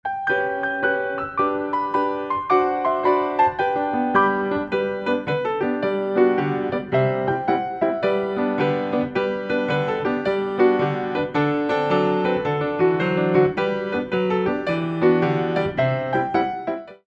29 Original Piano Pieces for Ballet Class
Petit Allegro/Skips
fast 6/8 - 0:43